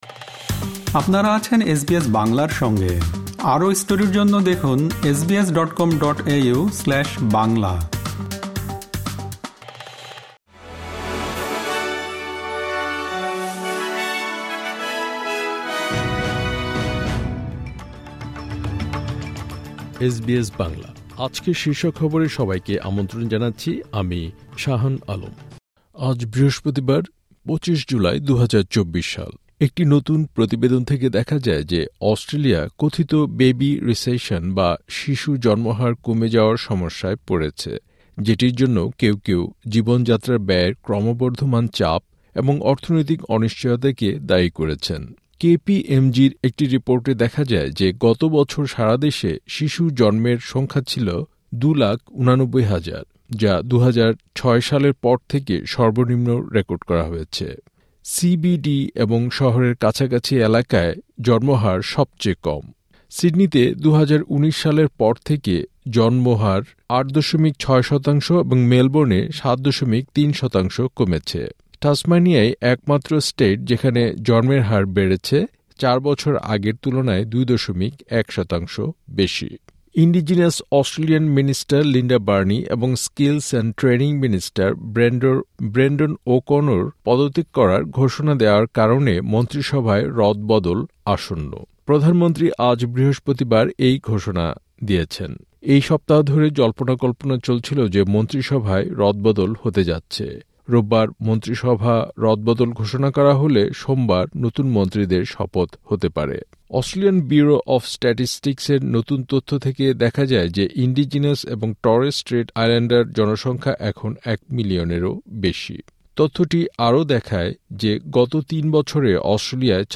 এসবিএস বাংলা শীর্ষ খবর: ২৫ জুলাই, ২০২৪